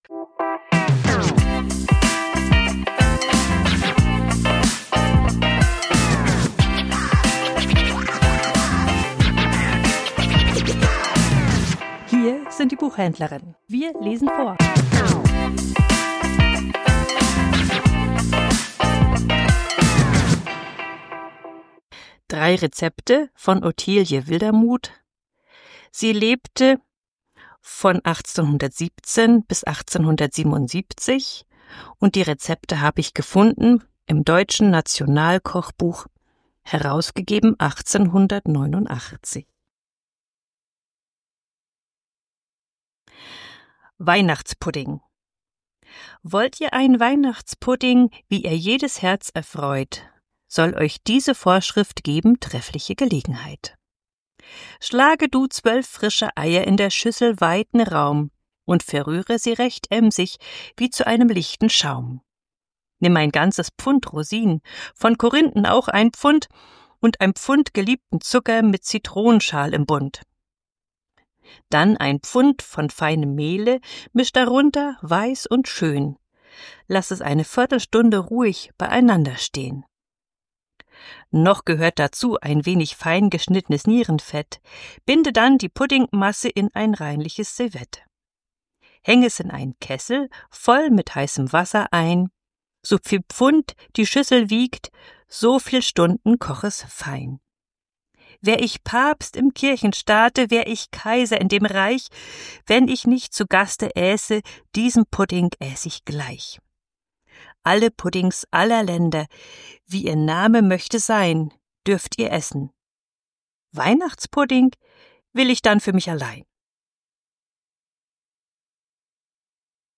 Vorgelesen: 3 Rezepte von Otilie Wildermuth